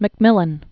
(mĭk-mĭlən), Edwin Mattison 1907-1991.